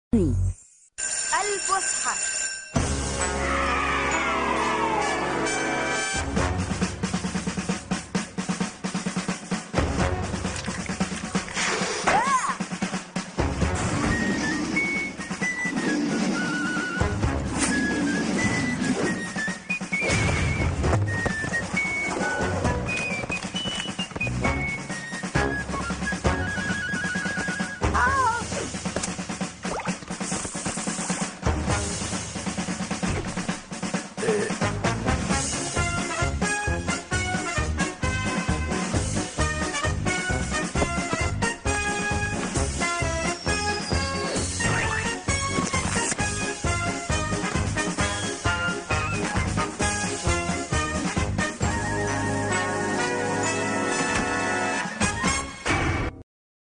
الفسحة - الحلقة 1 مدبلجة